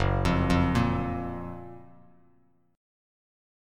F#M7 chord